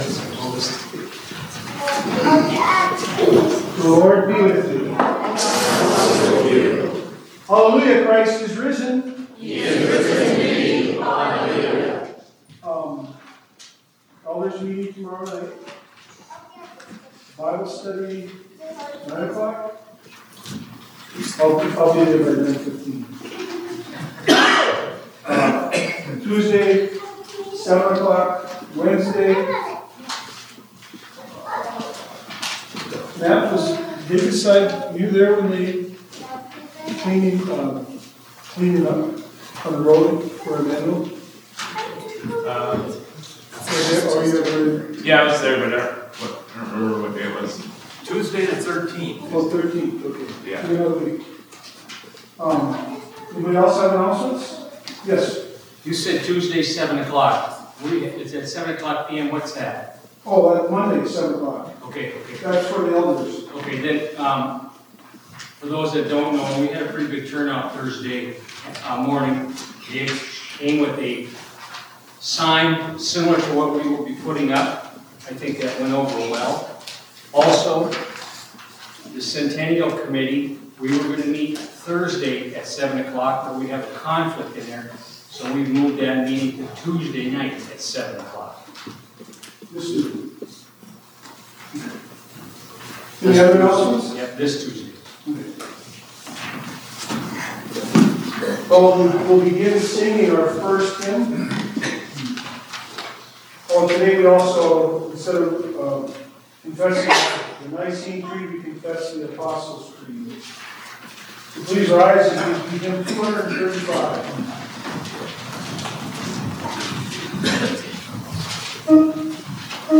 Zion Worship 4 May 25